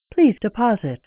Add .wav versions of the payphone sounds
please-deposit.wav